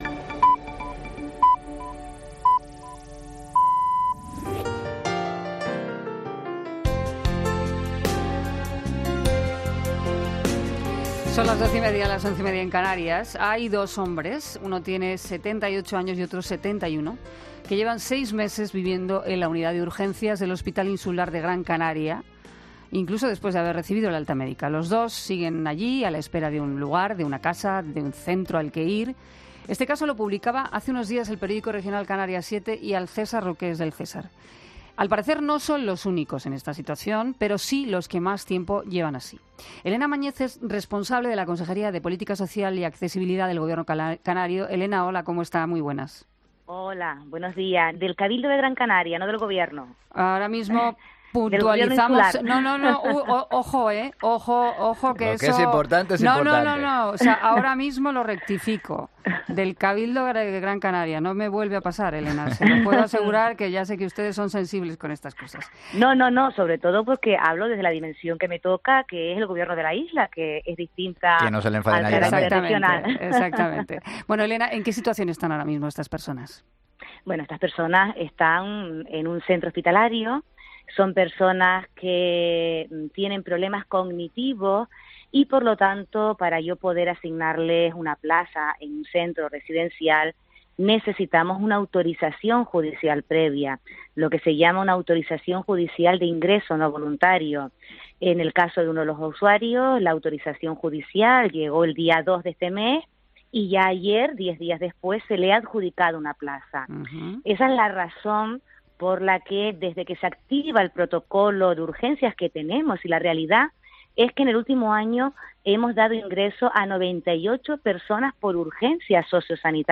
Elena Máñez, consejera de Política Social del Cabildo de Gran Canaria, en 'Mediodía COPE'